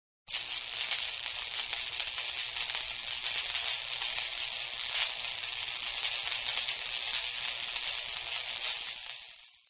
Electric Shock
Category: Sound FX   Right: Personal